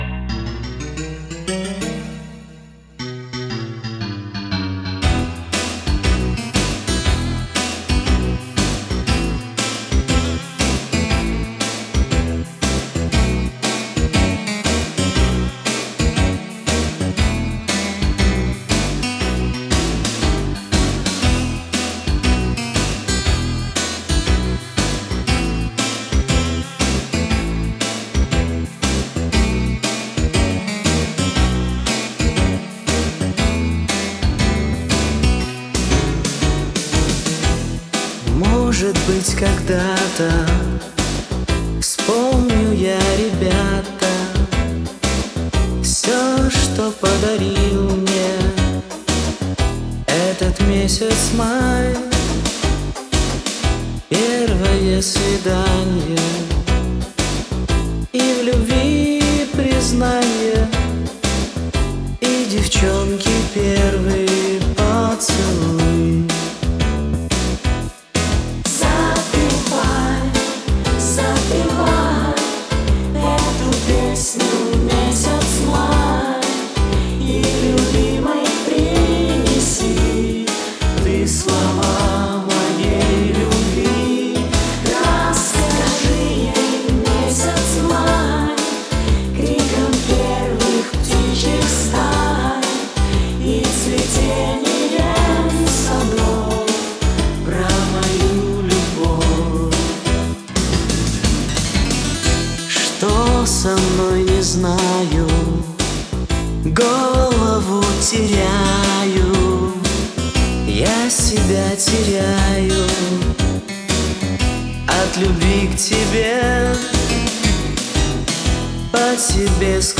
Жанр: Попса